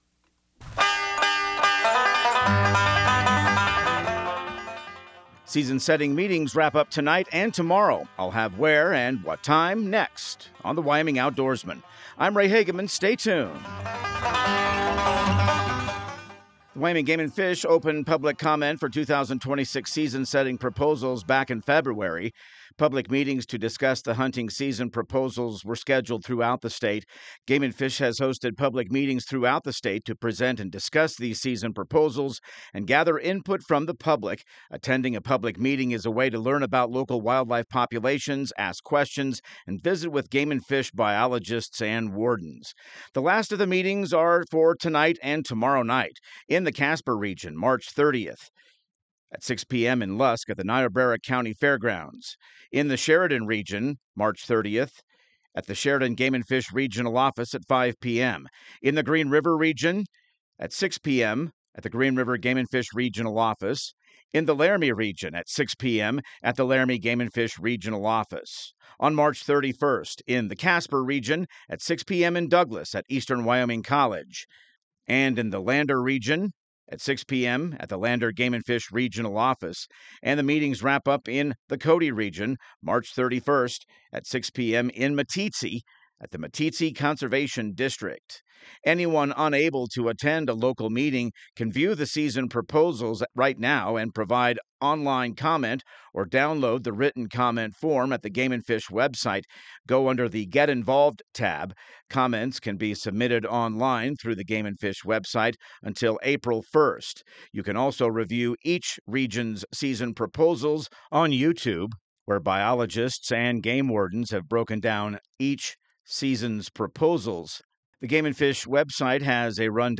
Radio news | Week of March 30